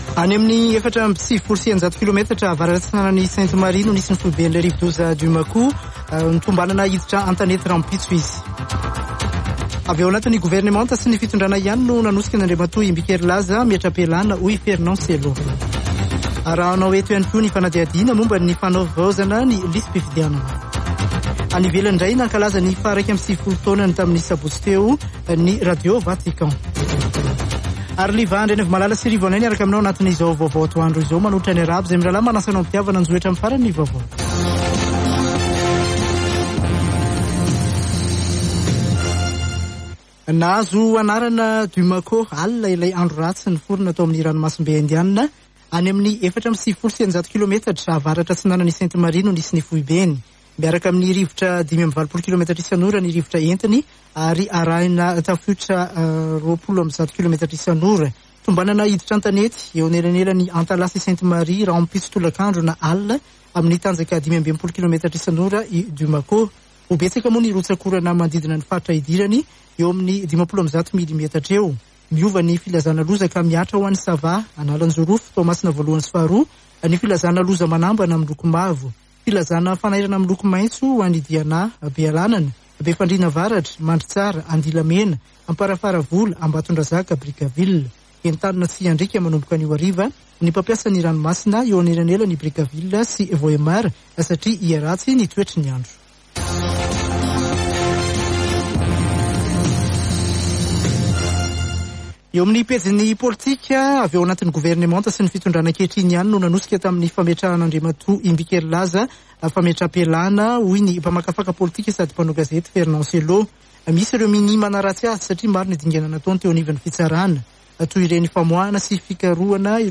Radio Don Bosco - [Vaovao antoandro] Alatsinainy 14 febroary 2022